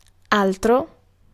Ääntäminen
US : IPA : /ə.ˈnʌð.ɚ/ UK : IPA : [ə.ˈnʌð.ə] UK : IPA : /əˈnʌðə(ɹ)/